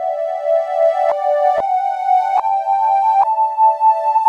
Synth 08.wav